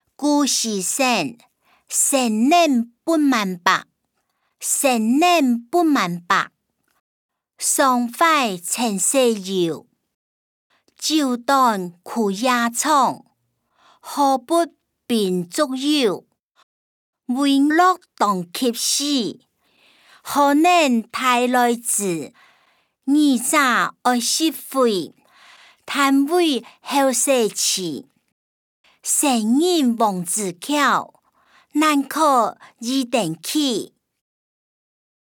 古典詩-生年不滿百音檔(饒平腔)